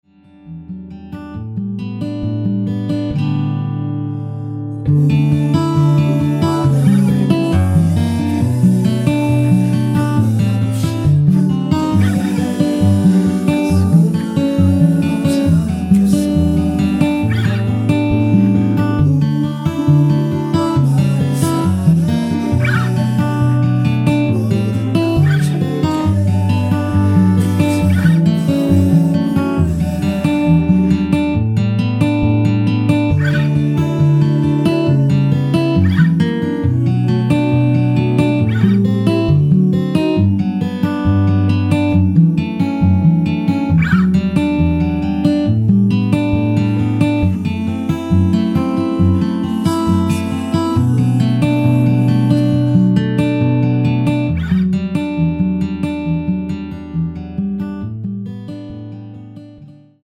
-1)코러스 포함된 MR 입니다.
앞부분30초, 뒷부분30초씩 편집해서 올려 드리고 있습니다.